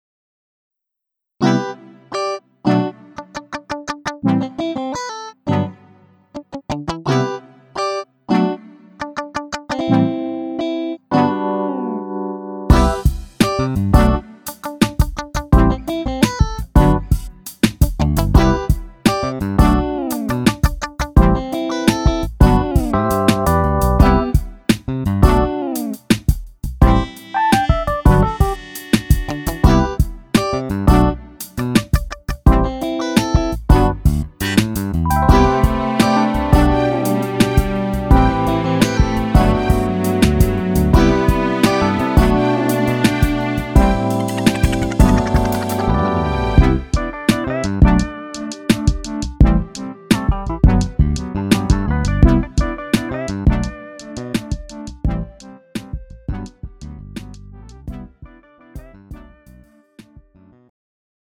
음정 -1키 3:21
장르 가요 구분 Pro MR